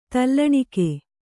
♪ tallaṇike